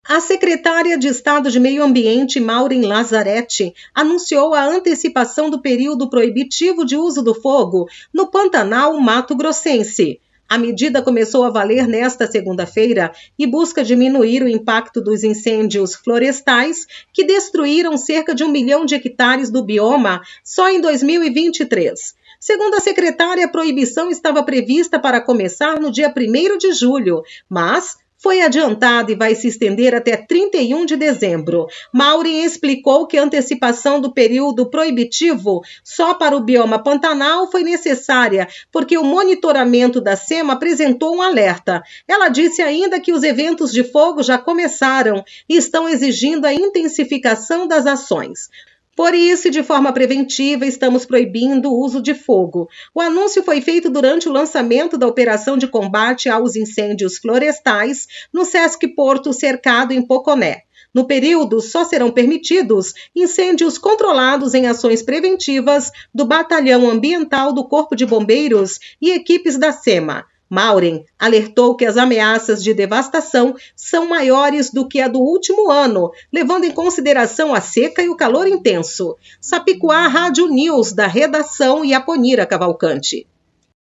Boletins de MT 18 jun, 2024